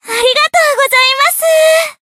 贡献 ） 分类:蔚蓝档案语音 协议:Copyright 您不可以覆盖此文件。
BA_V_Hanako_Battle_Recovery_1.ogg